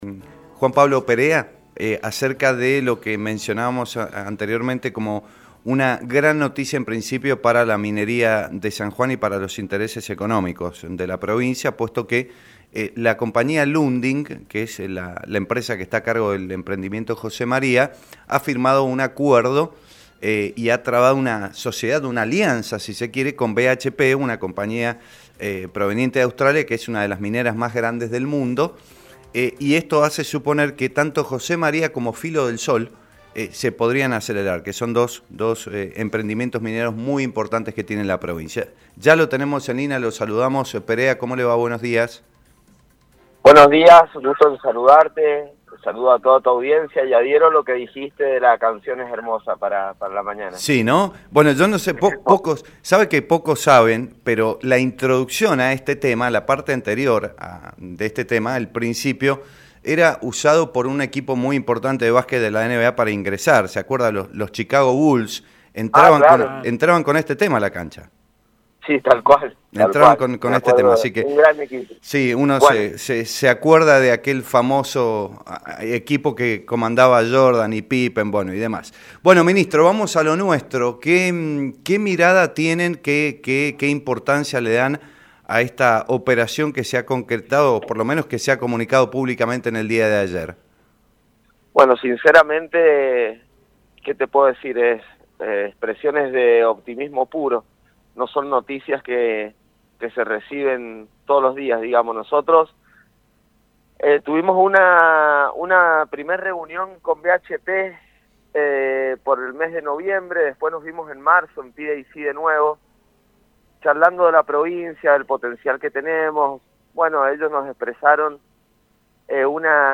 El ministro de Minería de San Juan, Juan Pablo Perea, mantuvo contacto con Estación Claridad y brindó detalles del líder mundial que desembarca en la provincia.